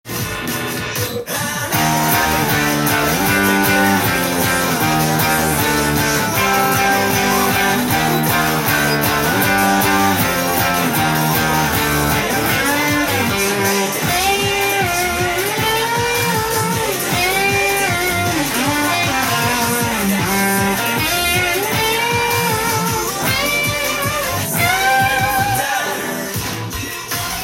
音源にあわせて譜面通り弾いてみました
最初のほうは、パワーコードで弾けます。
５段目から単音のギターパートを入れてみました。
チョーキングを多用してみたので、チョーキング練習にぴったりです。